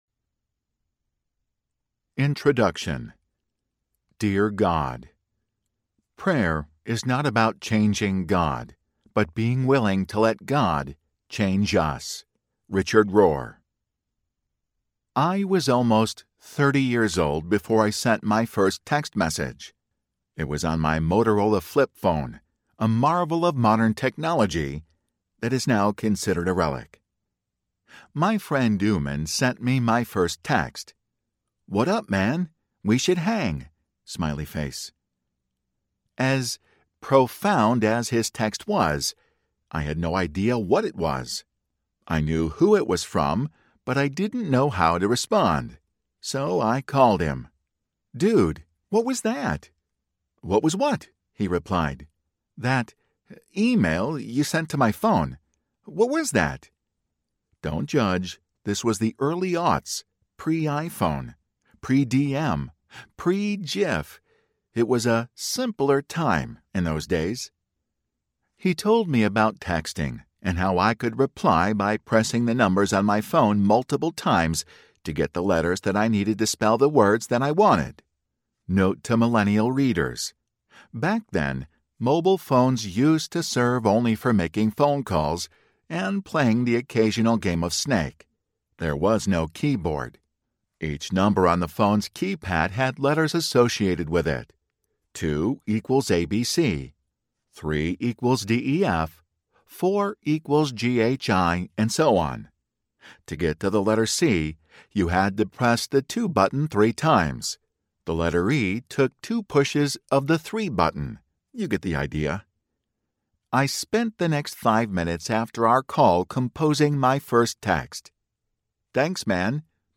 Praying Through Audiobook
Narrator
6.0 Hrs. – Unabridged